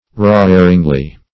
roaringly - definition of roaringly - synonyms, pronunciation, spelling from Free Dictionary Search Result for " roaringly" : The Collaborative International Dictionary of English v.0.48: Roaringly \Roar"ing*ly\, adv.